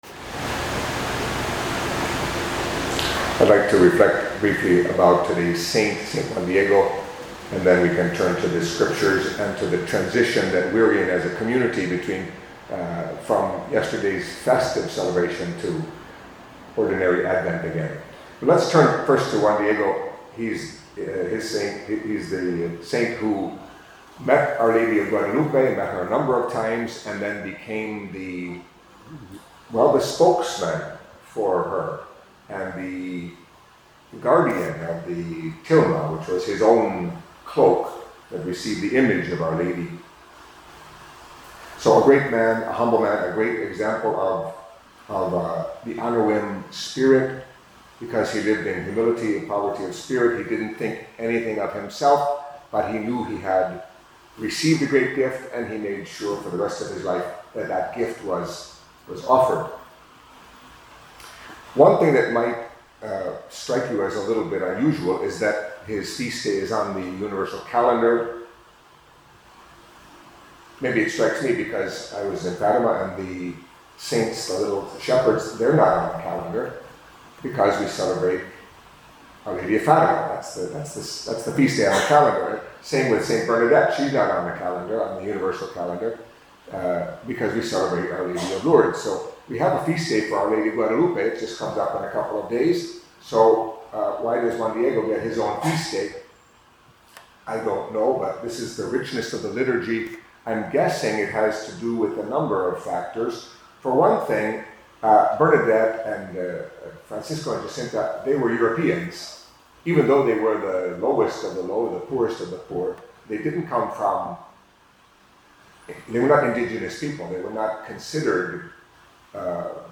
Catholic Mass homily for Tuesday of the Second Week of Advent